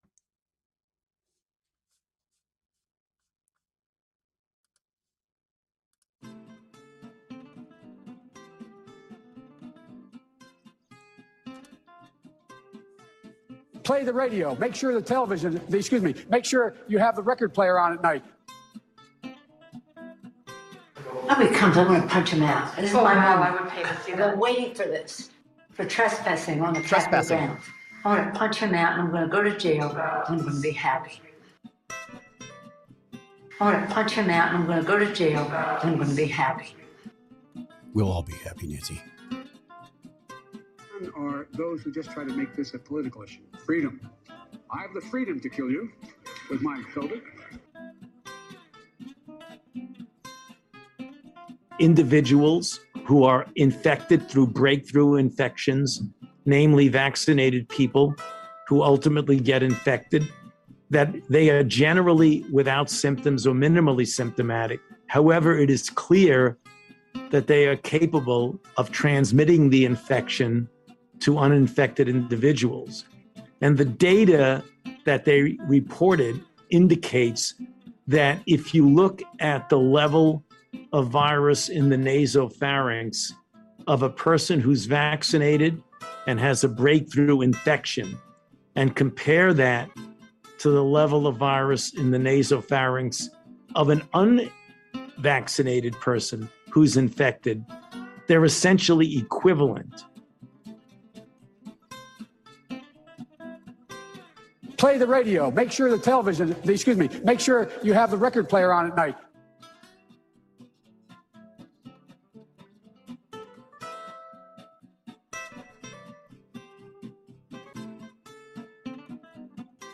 Today’s LIVE SHOW!